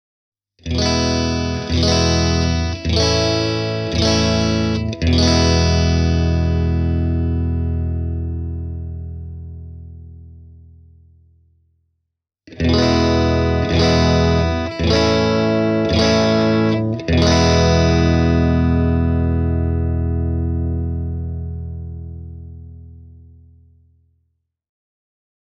Channel One on kaksikosta se helisevämpi ja kirkkaampi, kun taas Channel Two on hieman pyöreämpi ja rouheampi.
Tässä lyhyt pätkä puhtailla asetuksilla (molemmat volume-säätimet kello yhdeksän ja kymmenen välillä), vaahterakaulaisella Fender Stratocasterilla soitettuna: